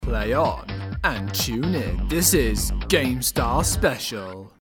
Category: Games   Right: Personal